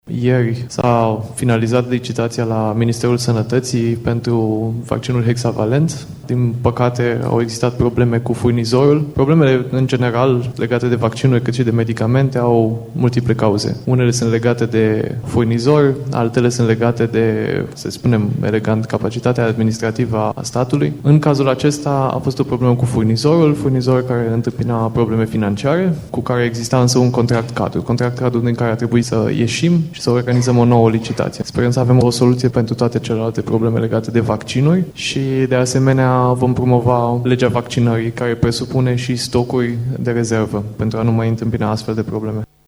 Ministrul Sănătății, Vlad Voiculescu, a anunțat că a fost finalizată procedura de atribuire a contractului pentru furnizarea acestui vaccin: